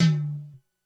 Index of /90_sSampleCDs/300 Drum Machines/Korg DSS-1/Drums01/06
HiTom.wav